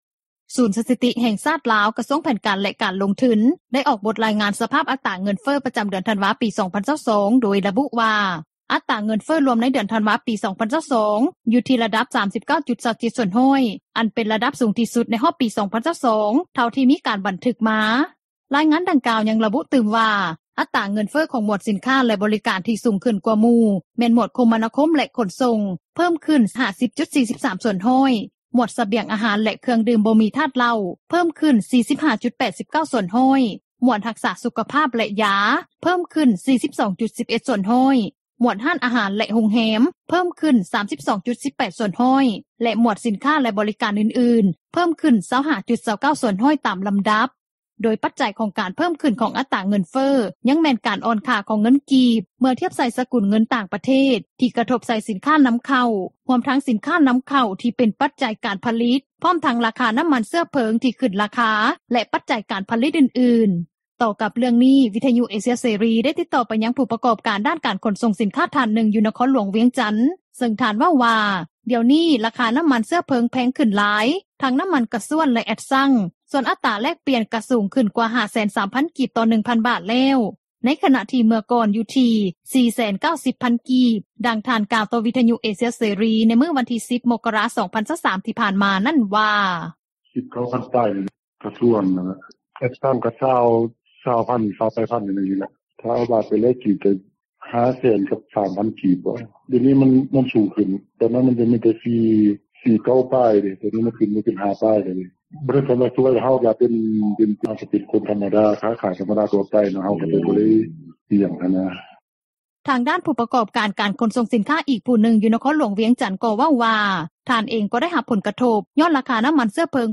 ຕໍ່ກັບເຣື່ອງນີ້ ວິທຍຸເອເຊັຽເສຣີ ໄດ້ຕິດຕໍ່ໄປຍັງຜູ້ປະກອບການ ດ້ານການຂົນສົ່ງສິນຄ້າ ທ່ານນຶ່ງ ຢູ່ນະຄອນຫຼວງວຽງຈັນ ເຊິ່ງທ່ານເວົ້າວ່າ ດຽວນີ້ ລາຄານໍ້າມັນເຊື້ອເພີງແພງຂຶ້ນຫຼາຍ ທັງນໍ້າມັນກາຊວນ ແລະແອັດຊັງ ສ່ວນອັດຕຣາແລກປ່ຽນ ກະສູງຂຶ້ນກວ່າ 503,000 ກີບ ຕໍ່ 1,000 ບາທແລ້ວ ໃນຂະນະທີ່ເມື່ອກ່ອນ ຢູ່ທີ່ 490,000 ກີບ.